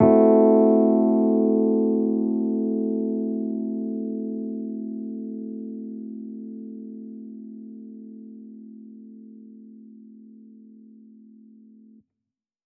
Index of /musicradar/jazz-keys-samples/Chord Hits/Electric Piano 2
JK_ElPiano2_Chord-Am6.wav